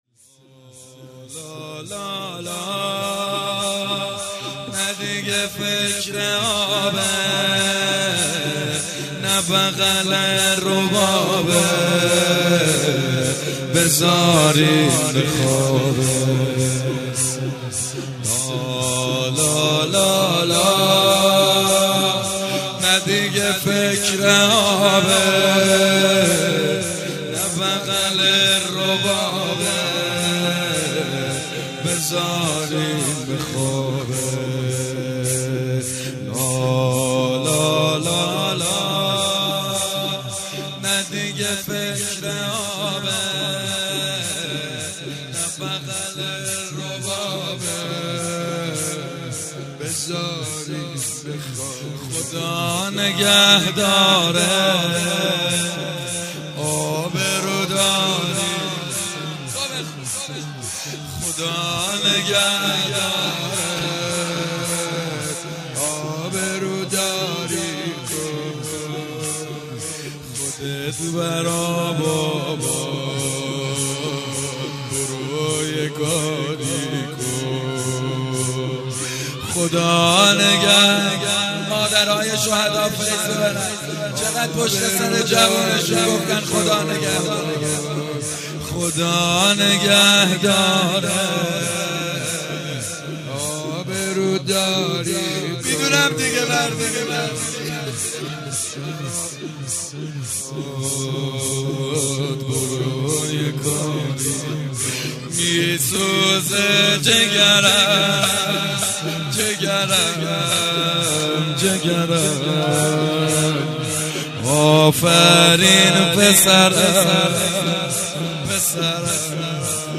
هئیت رزمندگان غرب تهران/رمضان96